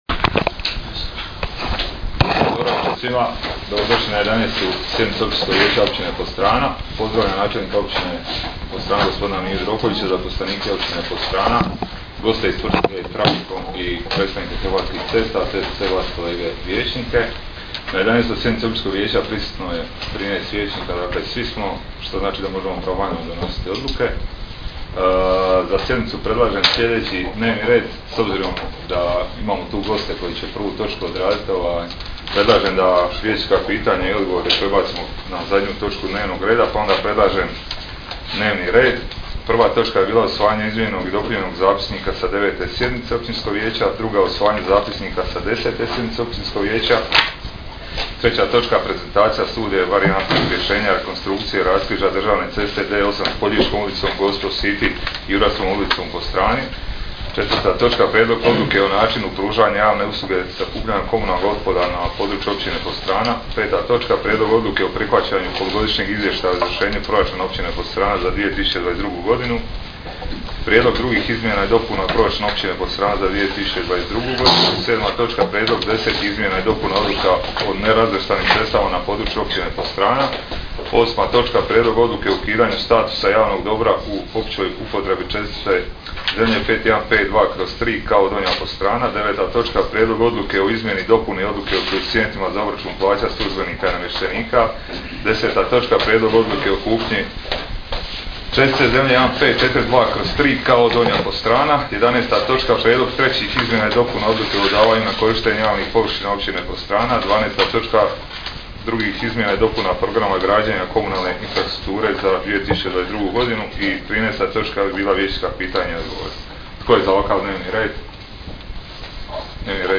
Sjednica će se održati dana 06. listopada (četvrtak) 2022. godine u 19,00 sati u vijećnici Općine Podstrana.